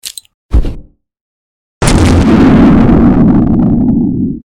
Grenade